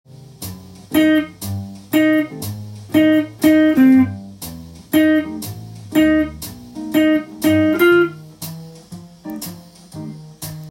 譜面通り弾いてみました
４分音符と４分休符を折ませながらそれらしい雰囲気が出せる